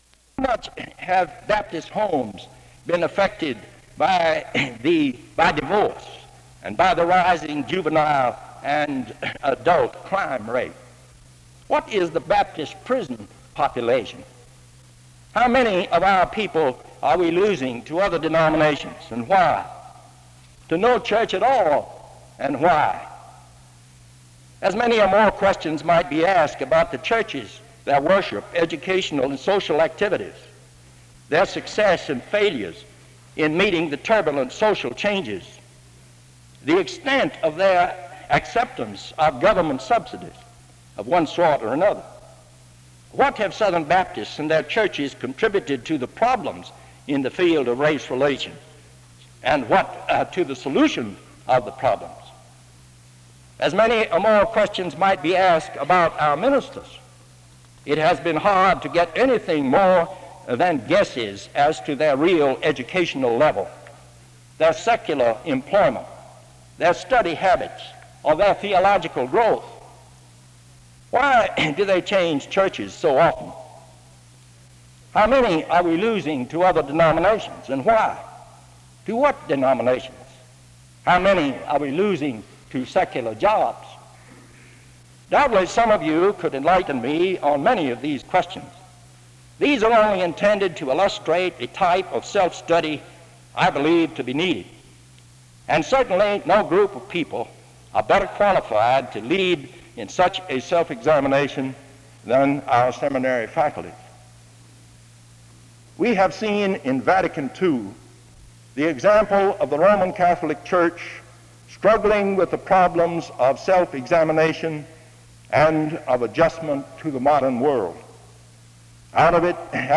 SEBTS Founder's Day Address
SEBTS Chapel and Special Event Recordings